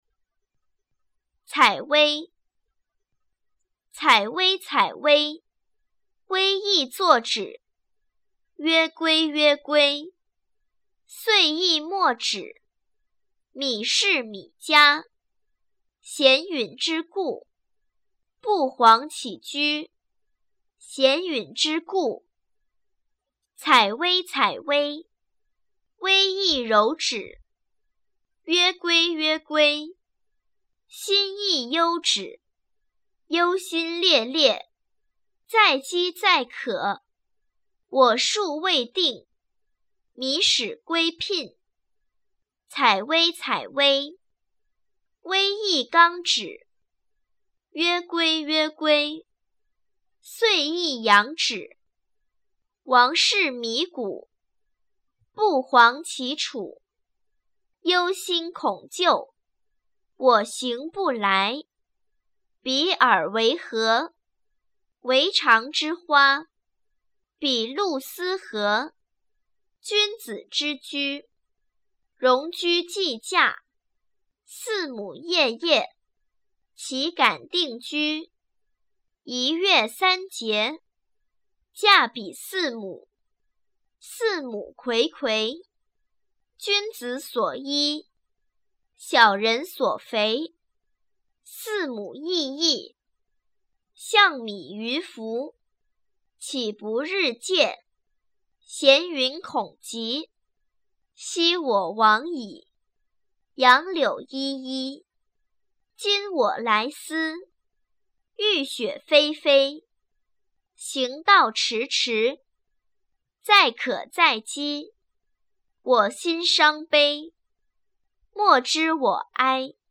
《采薇》原文与译文（含鉴赏与朗读）　/ 《诗经》